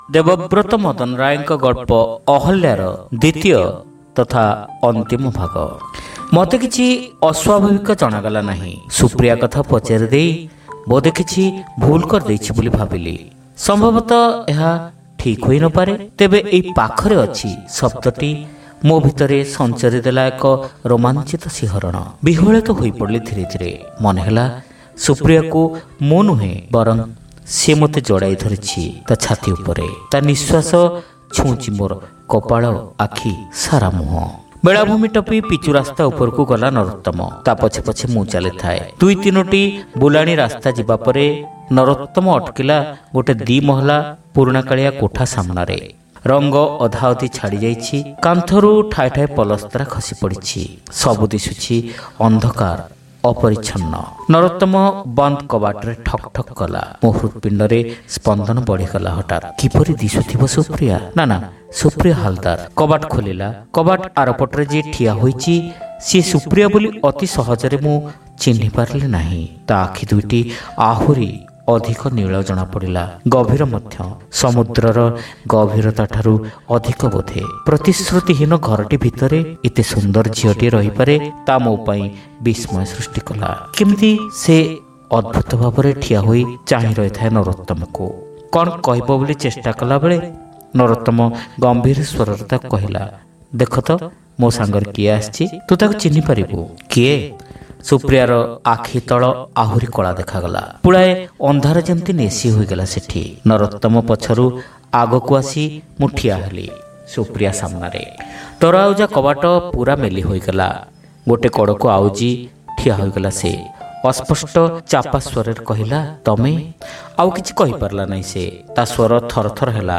ଶ୍ରାବ୍ୟ ଗଳ୍ପ : ଅହଲ୍ୟା (ଦ୍ୱିତୀୟ ଭାଗ)